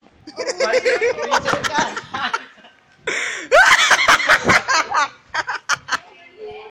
Risada Squirtle (Pokemon)
Risada do Squirtle do Pokemon.
risada-squirtle.mp3